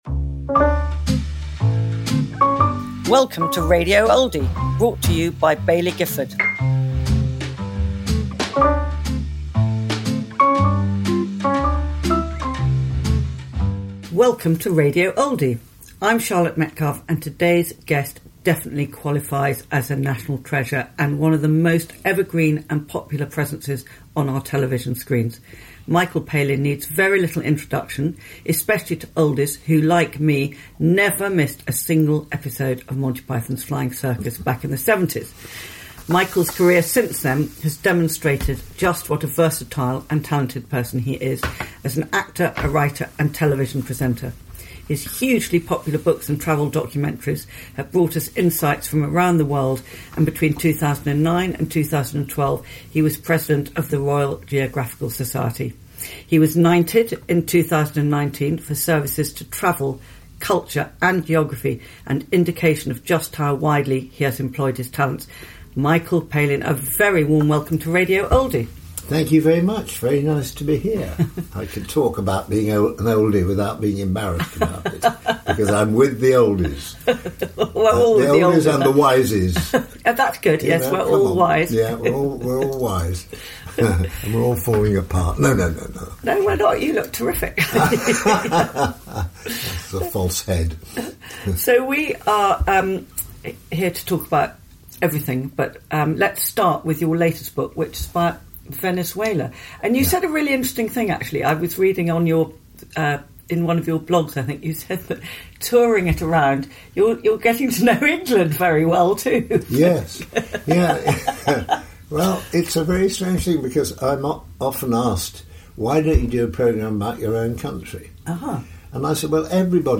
Michael Palin in conversation